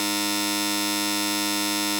ピン・ポン・パン・ポ～ン ビィーーー (開演ブザー) [ ブザー再生 ] 本日は「アンサンブル大会」におこしいただき、誠にありがとうございます。
buzzer.mp3